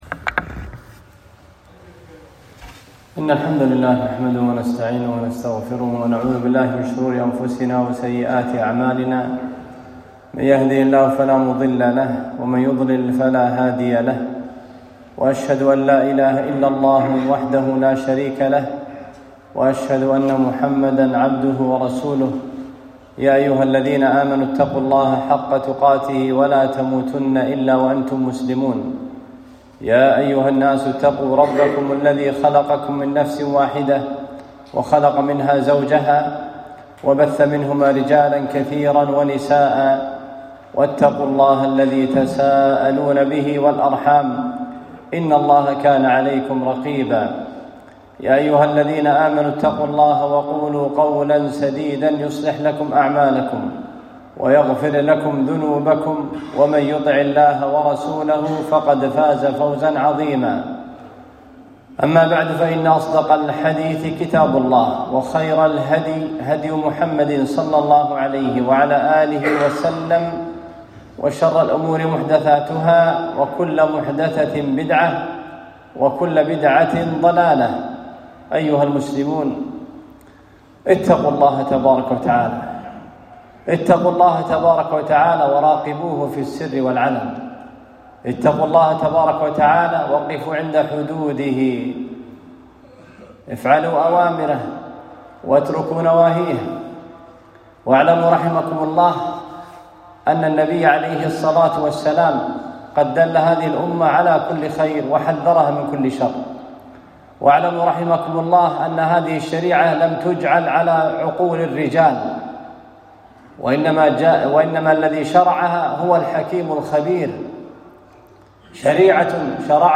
خطبة - موقف الشرع من الأعياد والاحتفالات الوطنية